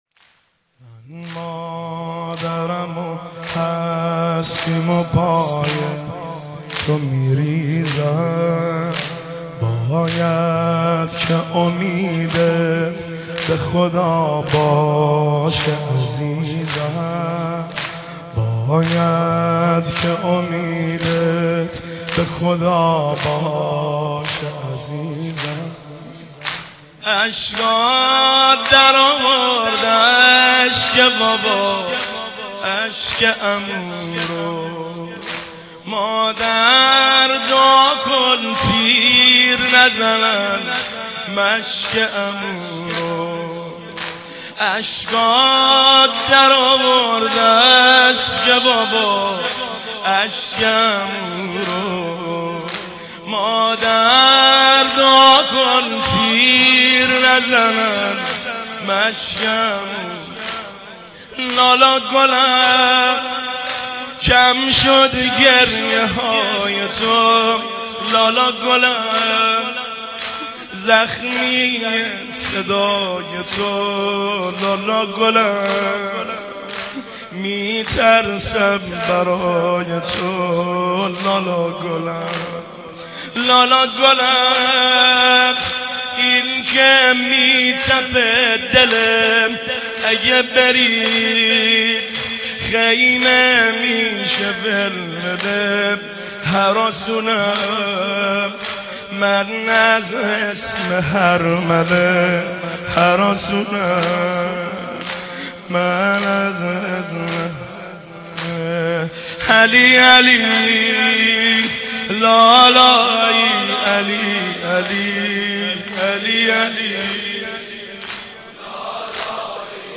شب هفتم محرم 95
مداحی
نوحه